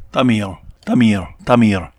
I've never tried to utter a retroflex approximant before, and while I think I might have pronounced it correctly, I think I'd have a hard time reliably distinguishing it from a retroflex tap or a voiced (really) alveolar approximant.
tamiltamiltamil.ogg